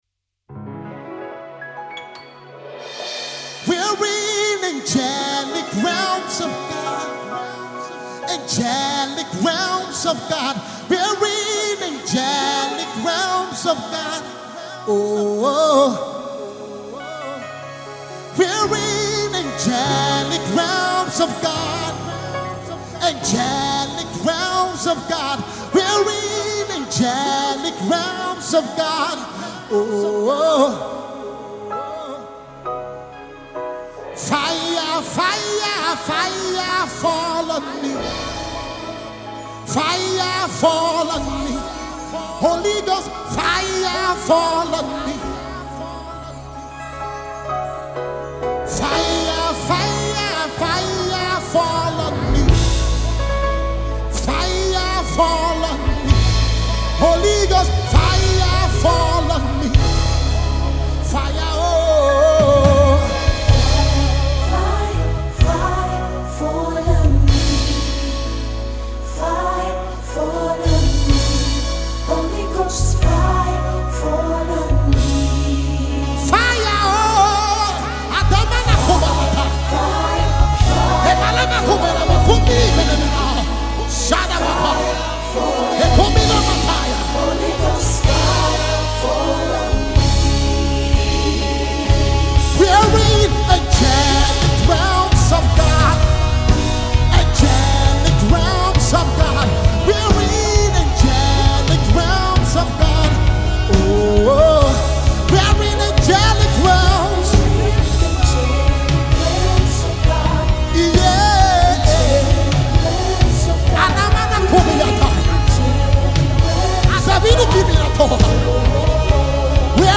He is a spiritual worshipper and chanter.